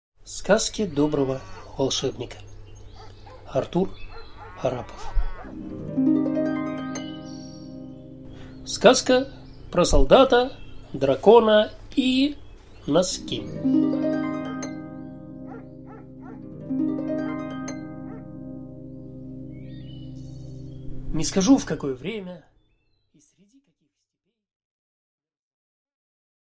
Аудиокнига Сказки доброго волшебника. Сборник | Библиотека аудиокниг